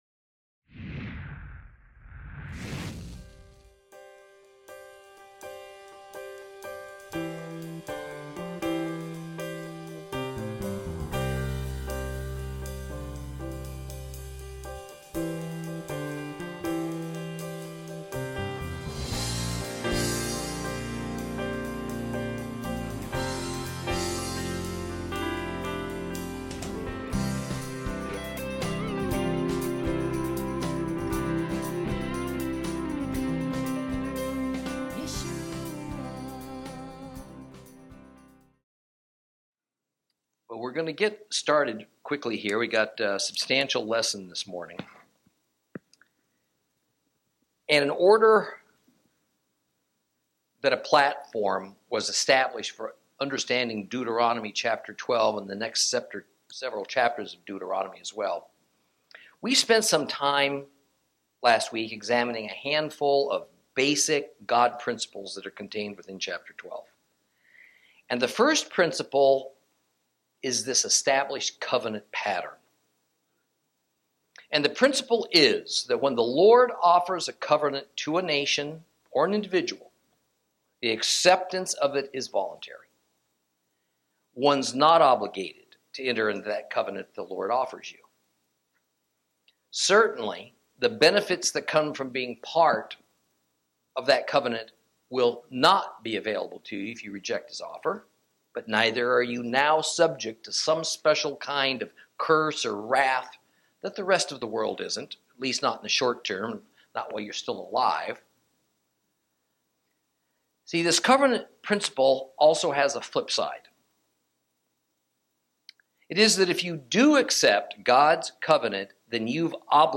Lesson 15 Ch12 - Torah Class